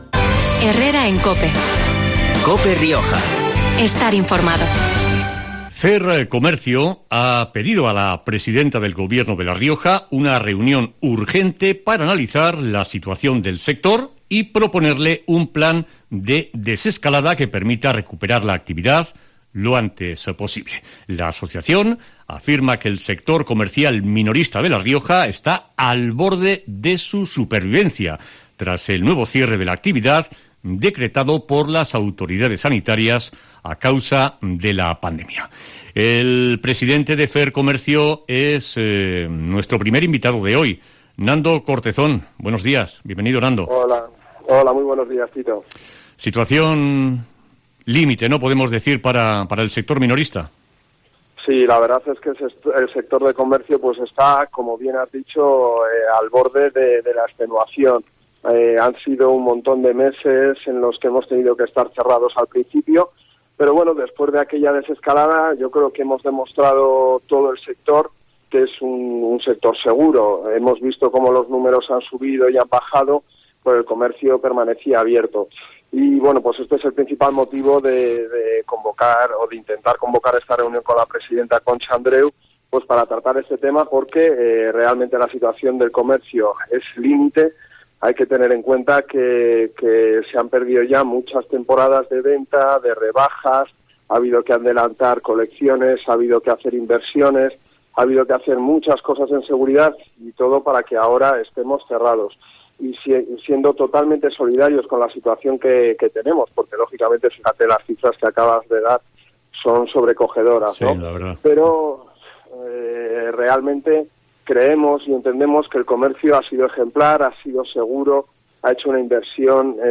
Entrevista en COPE Rioja